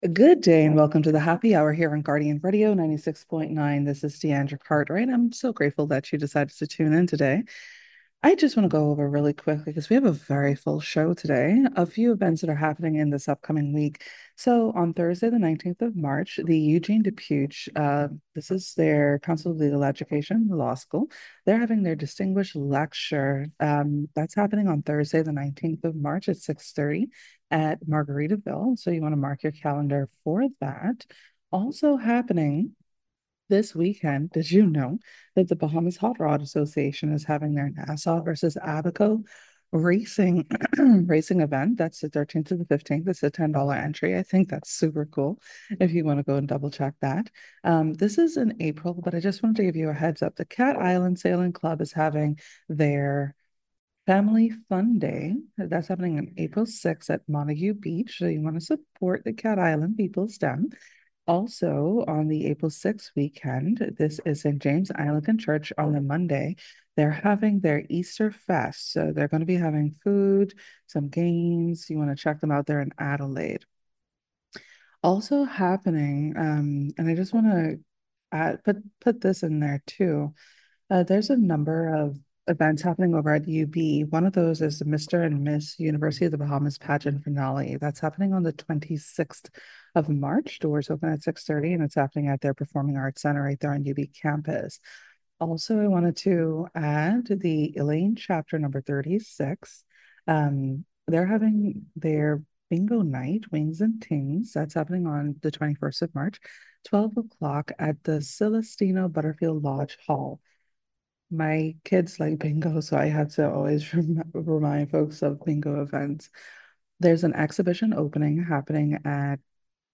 Saturday 14 March 2026, we will be hosting representatives of Women Walk Bahamas.